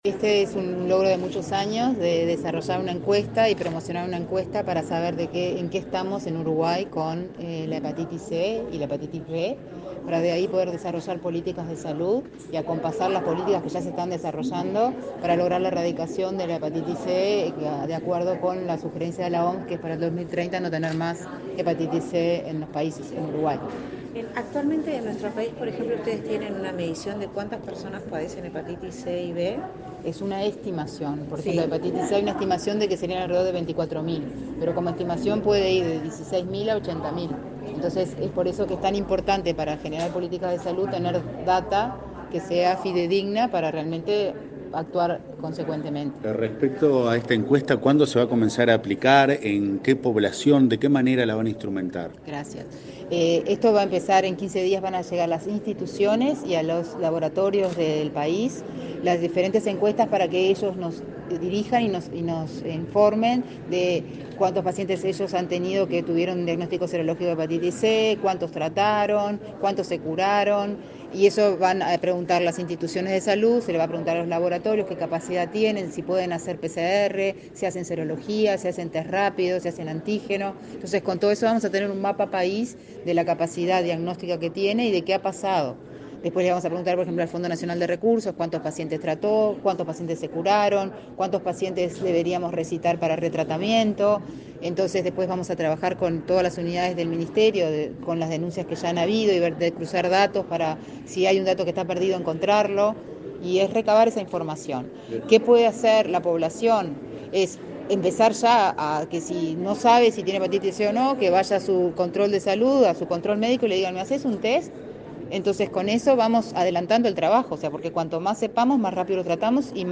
Luego, dialogó con la prensa.